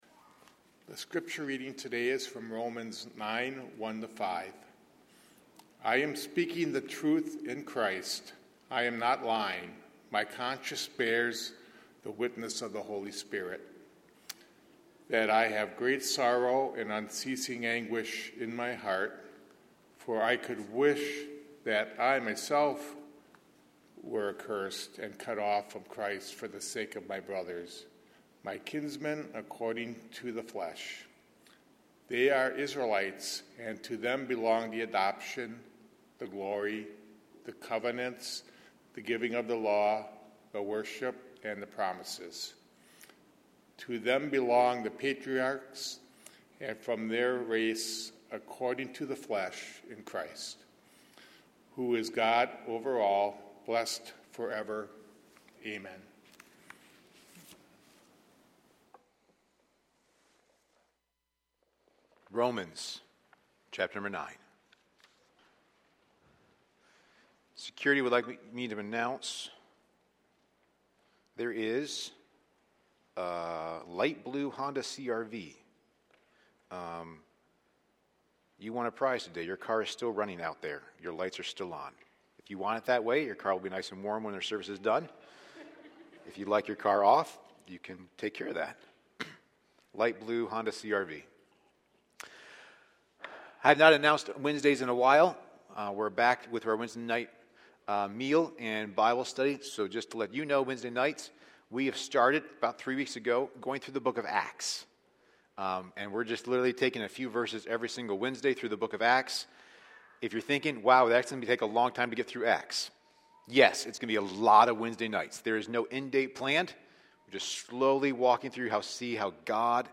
A sermon from the series "Romans."